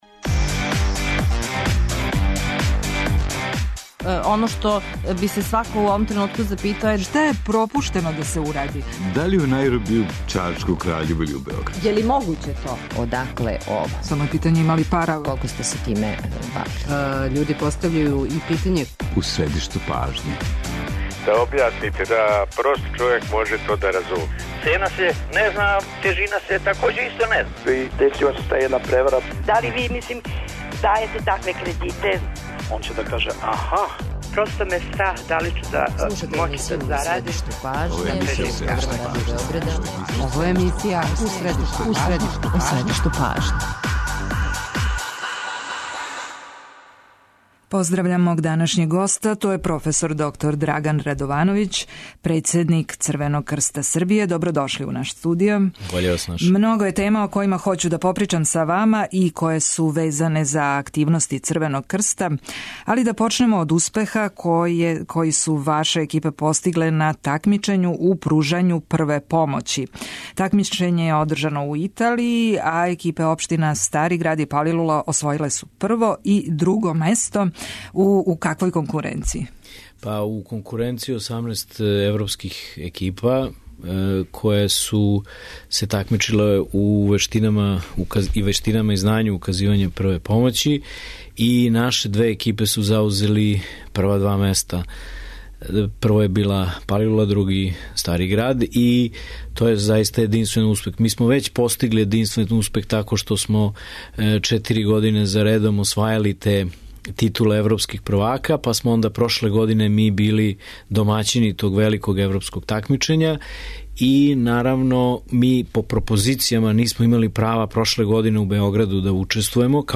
Биће то разговор о солидарности, о томе како у тешким временима наћи енергије и воље да се помогне онима који су у невољи.